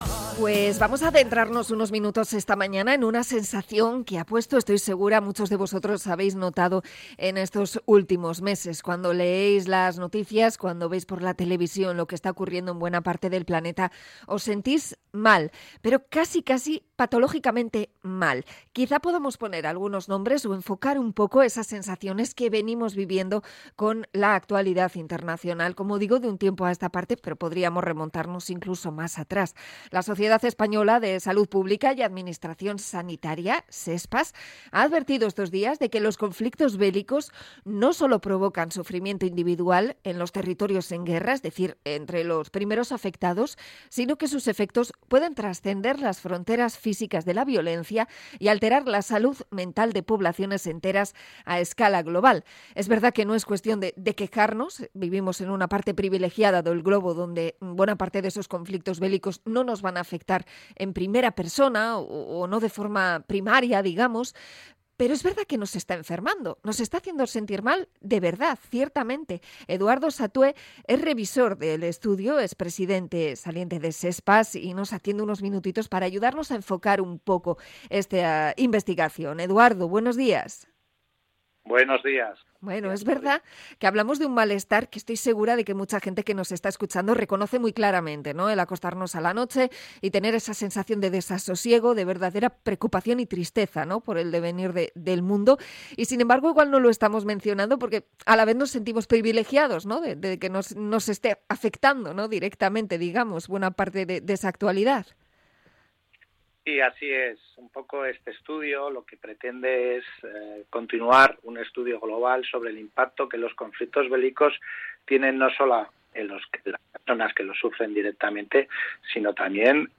Entrevista a SESPAS sobre el impacto de las guerras en la salud pública